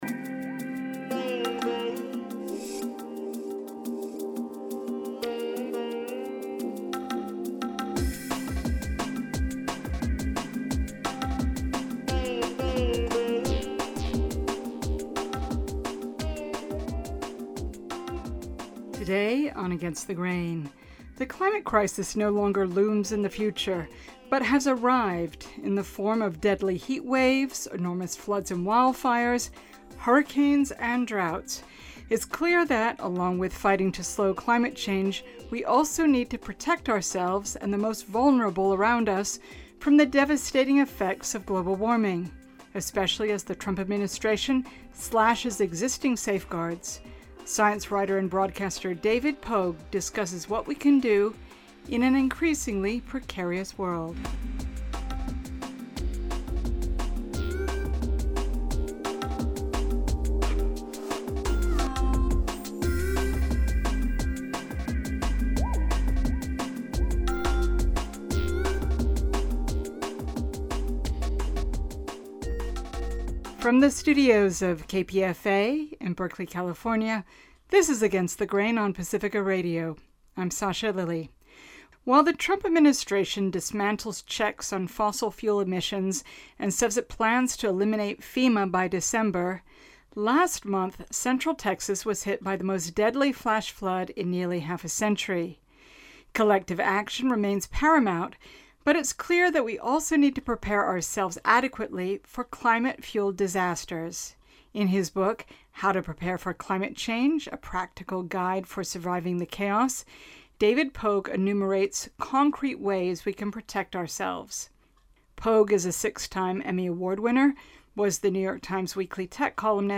Recorded January 20, 2026 in the KPFA studios.